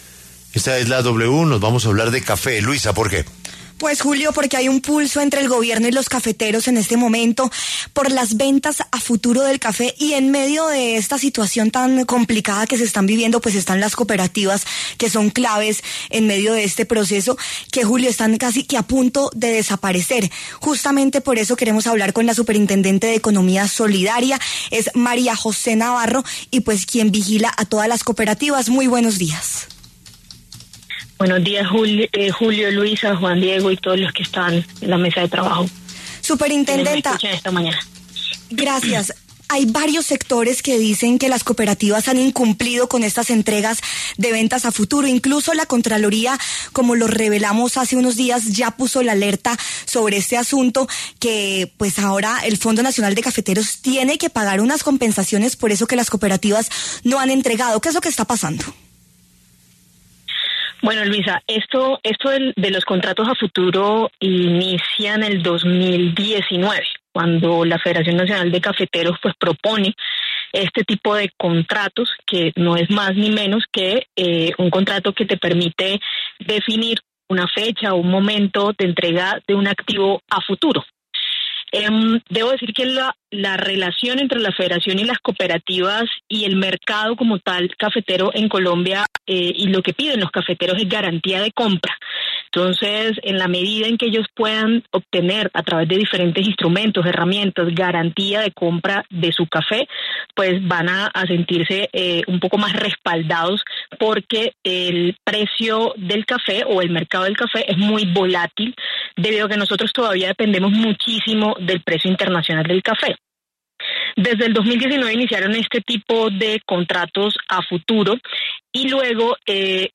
María José Navarro, superintendente de Economía Solidaria, se pronunció en La W sobre el pulso que hay entre el Gobierno y los cafeteros por el programa de ventas a futuro de café, donde en el medio están las cooperativas de cafeteros, que están casi a punto de desaparecer.